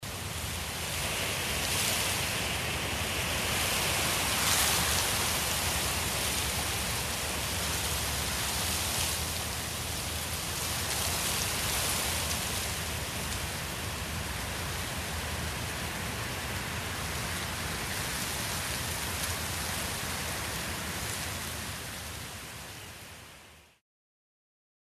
Звуки листьев
Шелест осенней листвы, легкий ветер в кронах деревьев или хруст под ногами — идеально для расслабления, работы или творчества.